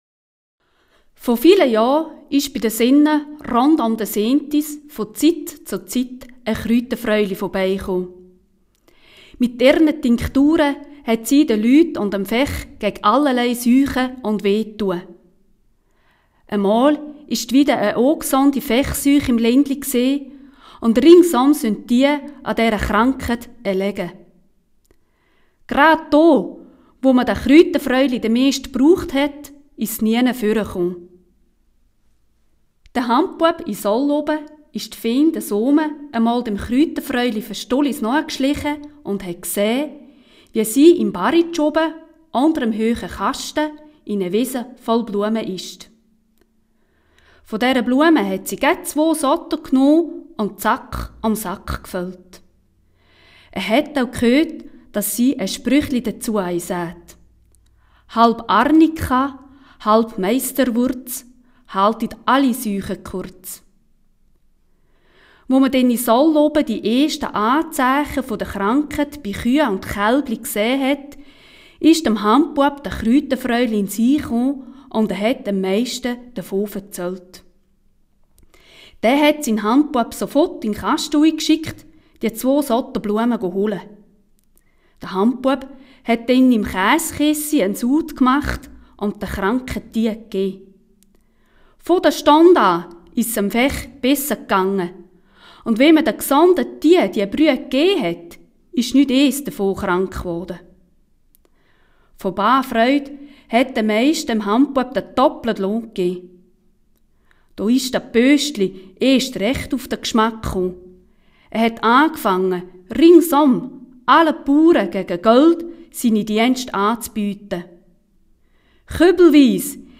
Hier finden Sie die Sage zum Nachlesen auf Hochdeutsch.